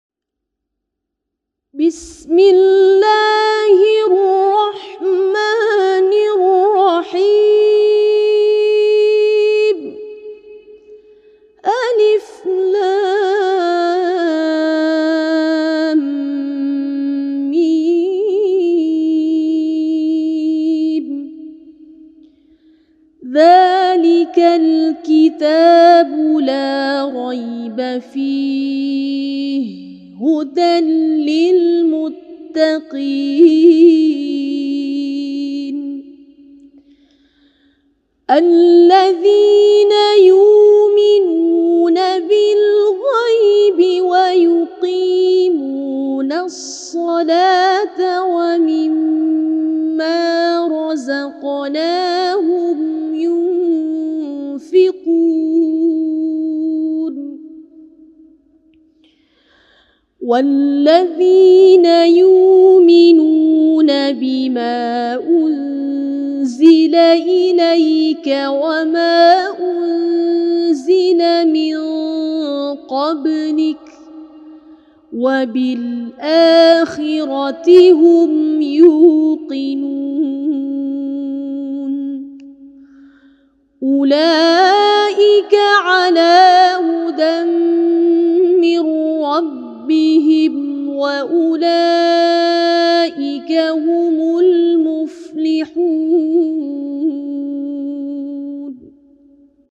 Qira’at Imam Abu Amru Riwayat As-Susi
1-Qiraat-Imam-Abu-Amru-AlBaqarah-1sd4-Riwayat-AsSusi.mp3